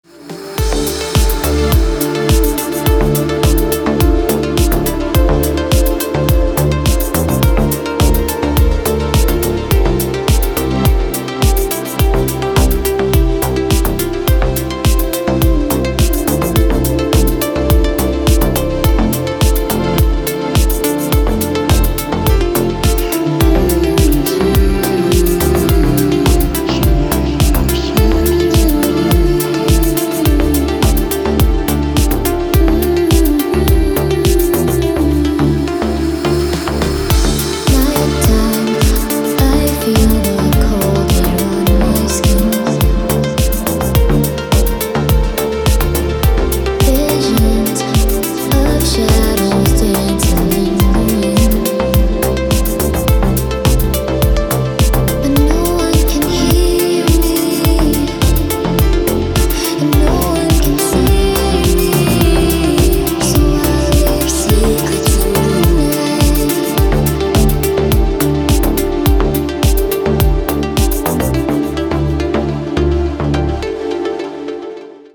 Рингтоны релакс треков